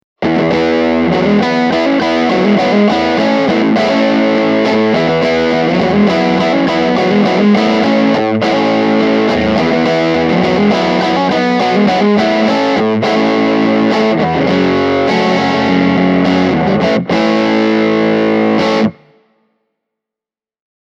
JTM:n särösoundit ovat miedompia ja lämpimämpiä kuin JMP:n. JMP1C on säröllä runsaasti tuoreempi ja rapeampi ja se kuulostaa enemmän vahvistinstäkiltä kuin JTM, jonka sointi on hyvin Bluesbreaker-tyylinen:
Marshall JTM1C – Les Paul Junior/särö